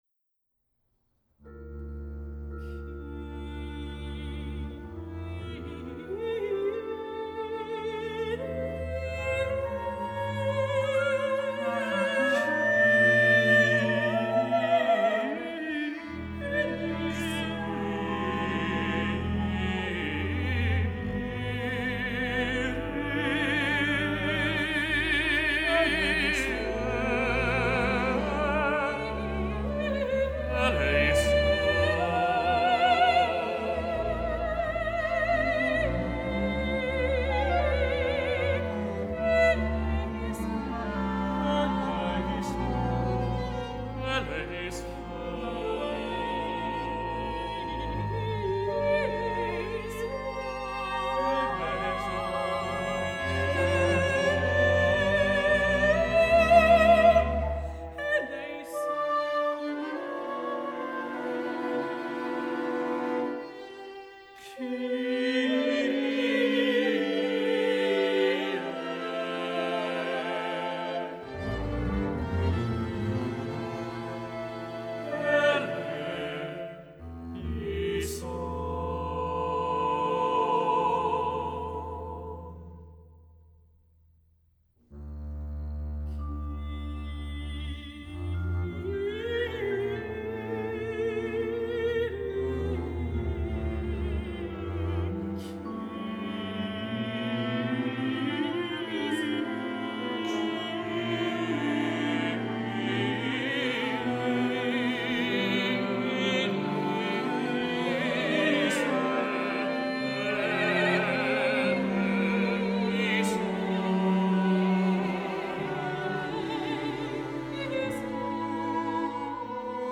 soprano
mezzo-soprano
tenor
bass-baritone
flute
clarinet
viola
piano
percussion
The blend of idioms is starkly successful.